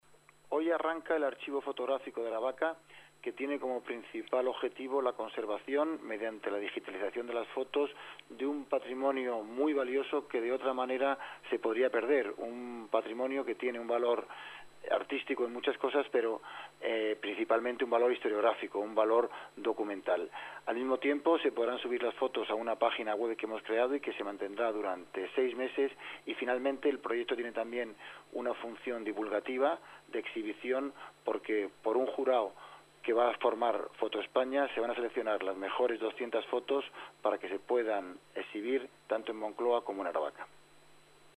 Nueva ventana:Alvaro Ballarín, concejal-presidente de Moncloa-Aravaca